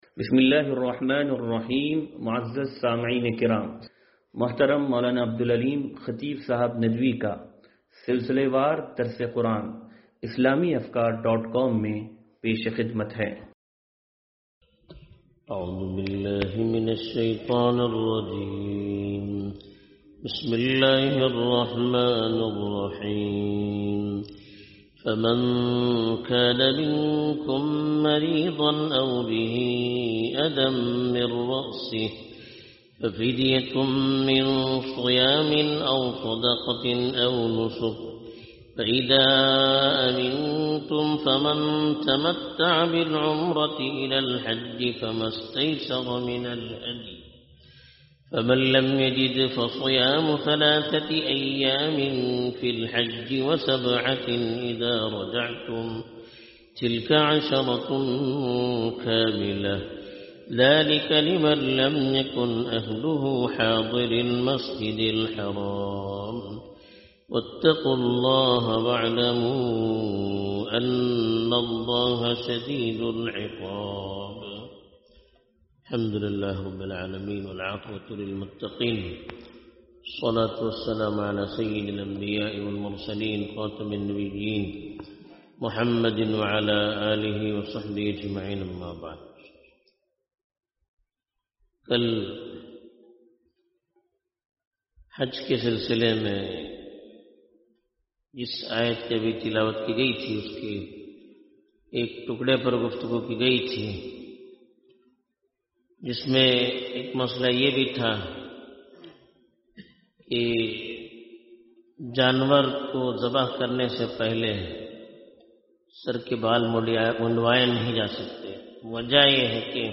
درس قرآن نمبر 0146